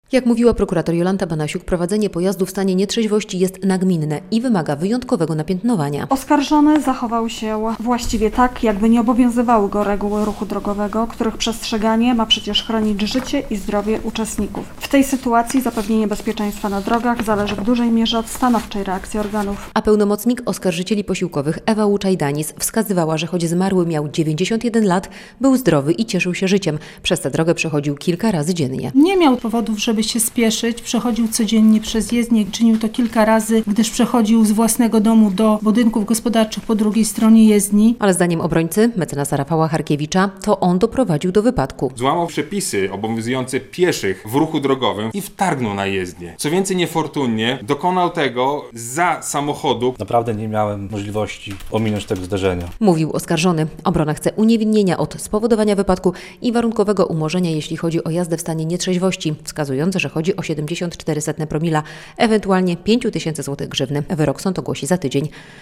Koniec procesu ws. śmiertelnego potrącenia pieszego przez motocyklistę - relacja